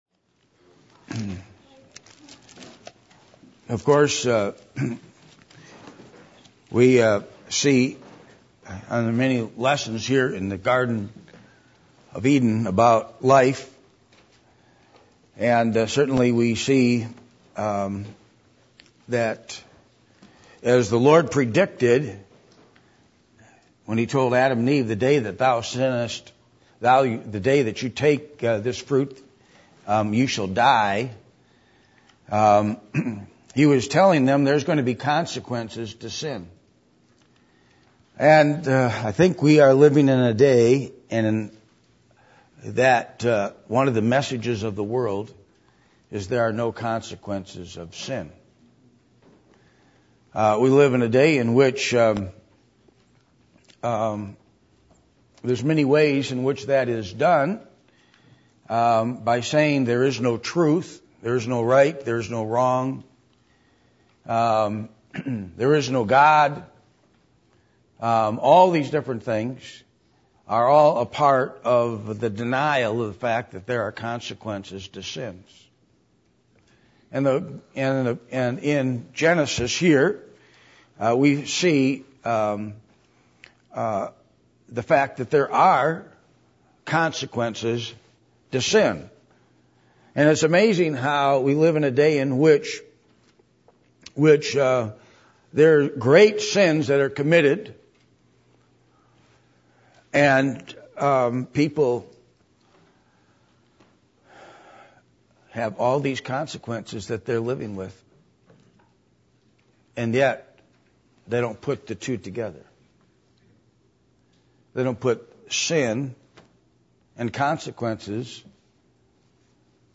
Genesis 3:1-15 Service Type: Sunday Morning %todo_render% « A Testimony Worth Standing For Who Are Your Counselors?